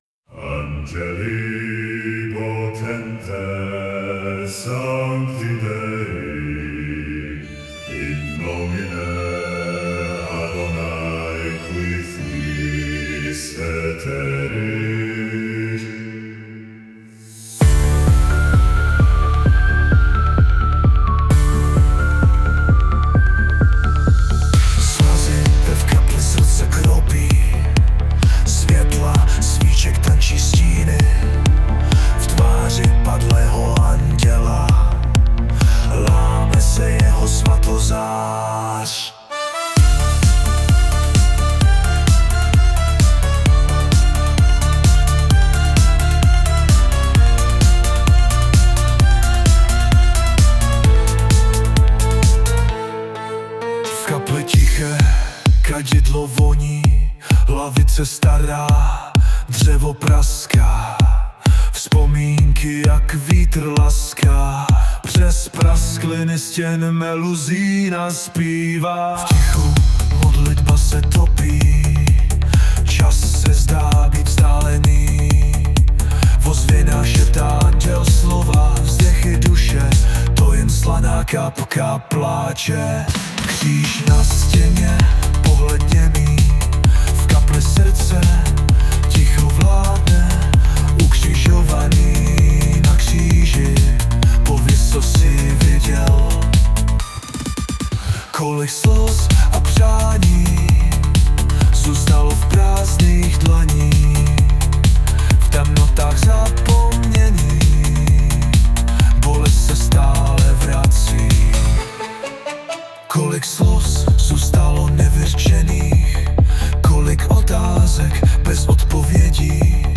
2025 & Hudba, zpěv a obrázek: AI
Pěkně nazpíváno.
Výslovnost např: In nómine Patris, et Fílií et Spiritús Sanktí.